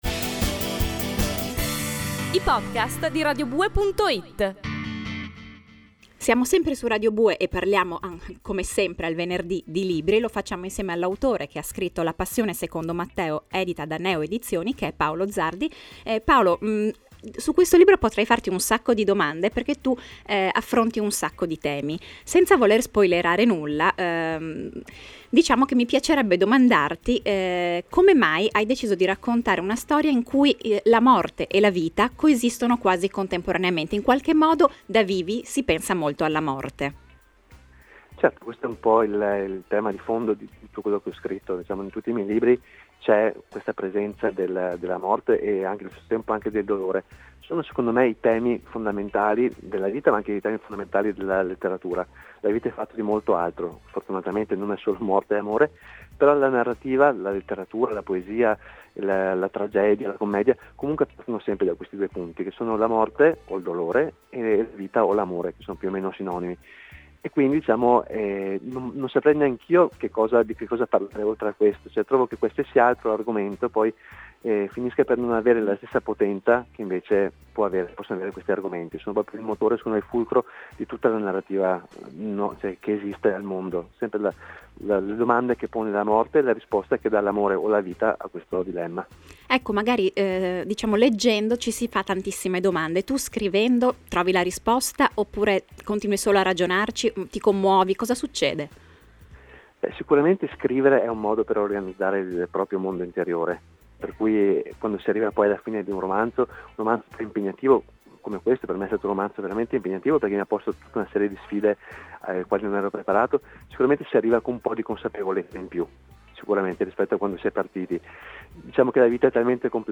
Questo è il gruppo che ascolta scrivendo, e, per scoprire il resto (e una serie di aneddoti curiosi), premi play sulla seconda parte dell’intervista (o scaricandola qui):